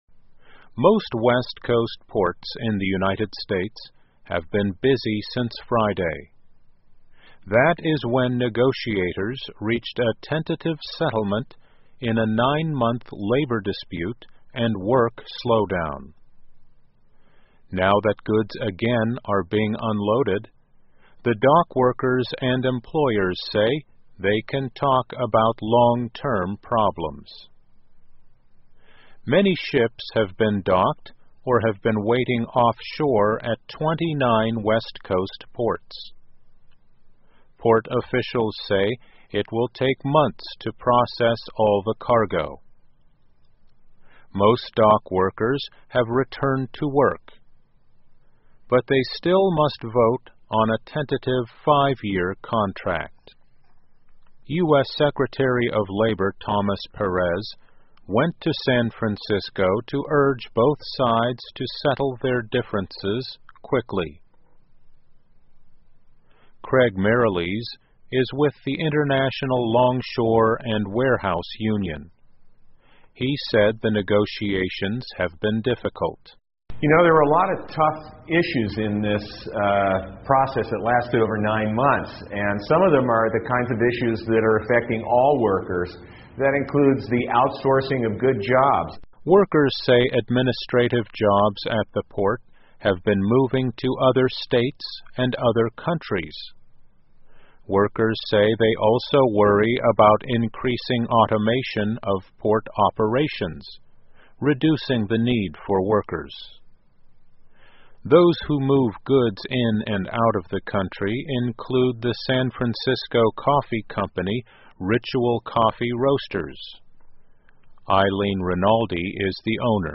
VOA慢速英语2015 美国西海岸港口恢复运作 听力文件下载—在线英语听力室